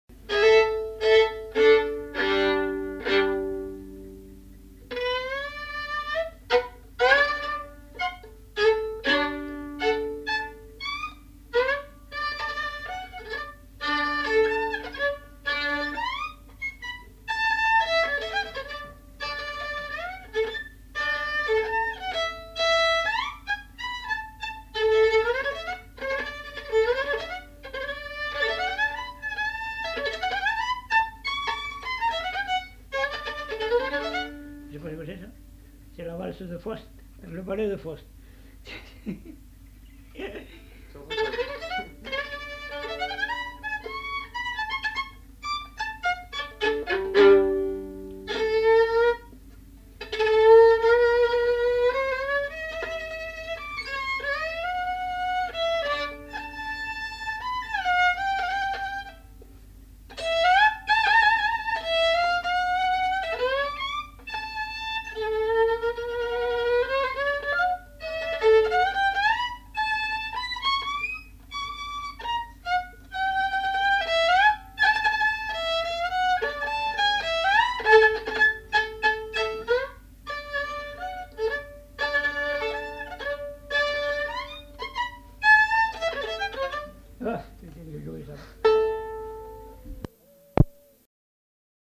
Aire culturelle : Lomagne
Genre : morceau instrumental
Instrument de musique : violon
Danse : valse